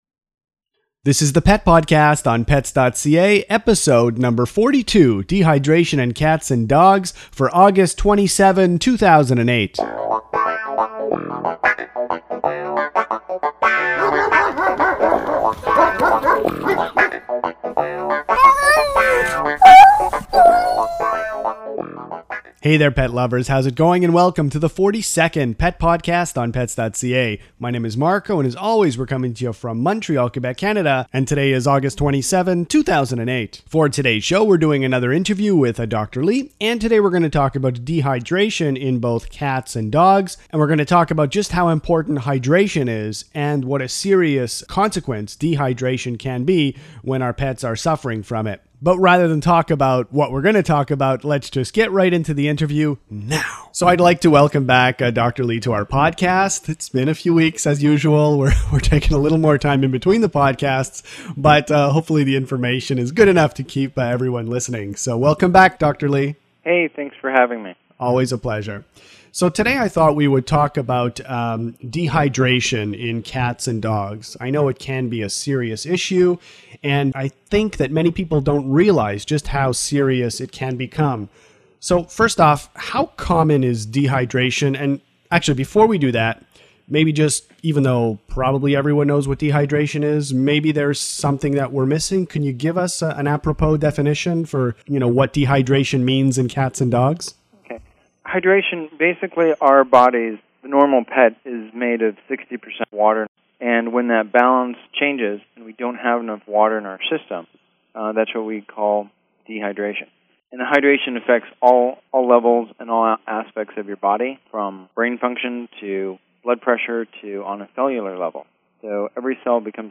In this interview we discuss dehydration in cats and dogs. We talk about why our pets get dehydrated, we talk about simple tests that may indicate dehydration and we talk about treating dehydration.